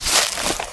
STEPS Bush, Walk 16.wav